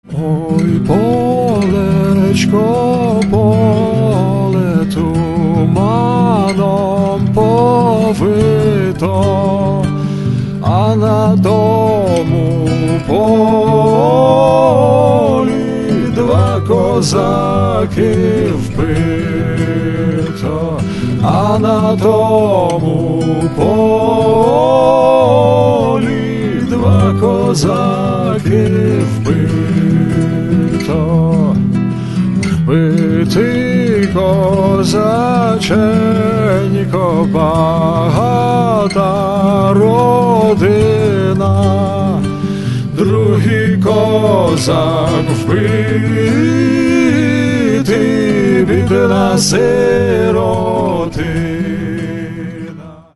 Каталог -> Народная -> Современные обработки